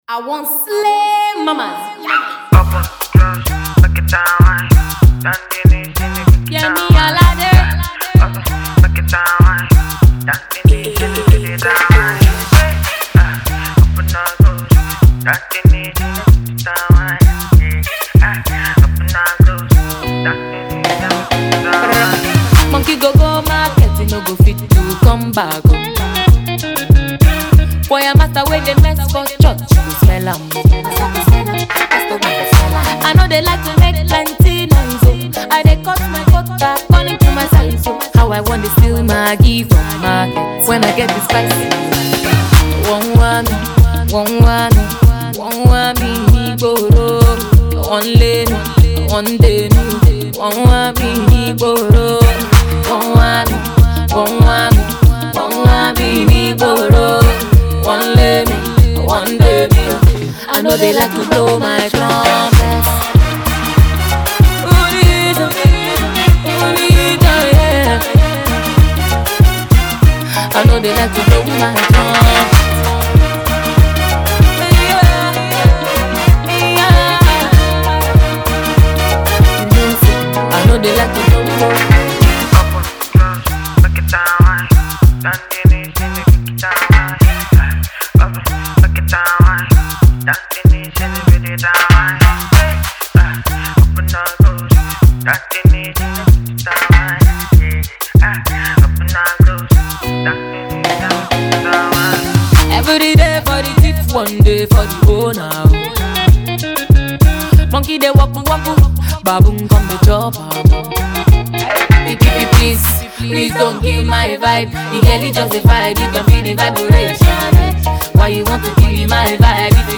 afrobeats banger